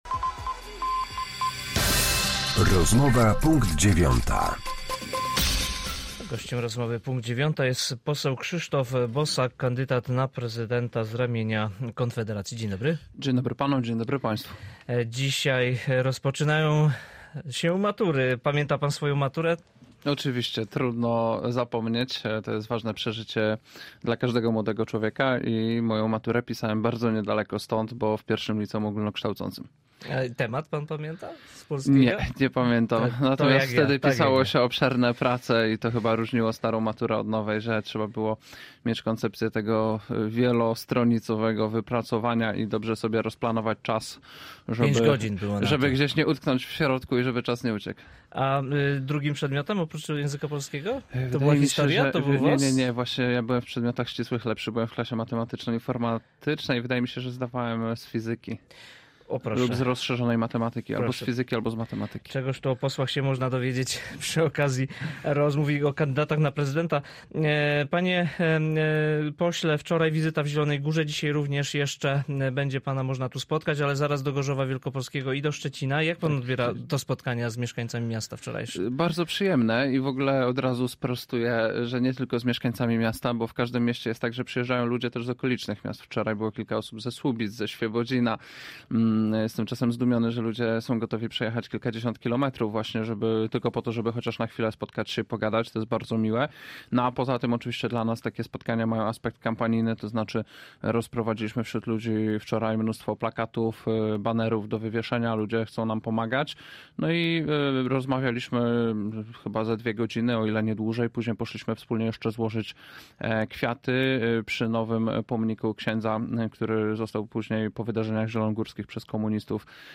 Z posłem Konfederacji, kandydatem na prezydenta RP rozmawia